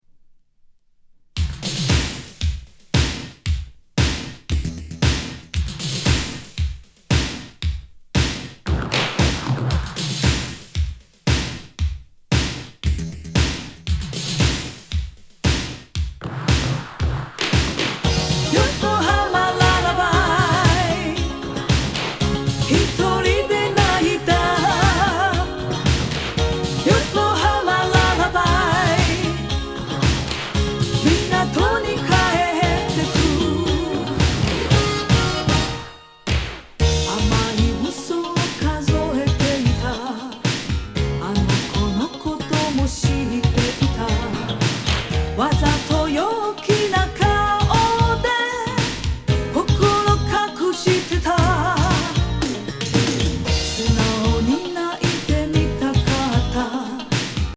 80’ｓモダンブギー
シンセ・ピキピキ